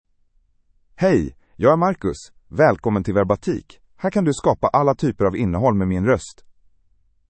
MaleSwedish (Sweden)
MarcusMale Swedish AI voice
Voice sample
Male
Marcus delivers clear pronunciation with authentic Sweden Swedish intonation, making your content sound professionally produced.